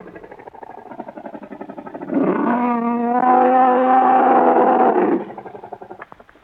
Monster Roar Groan